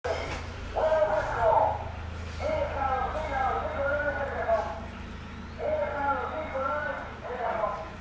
Vendedor de huevos CARTAGO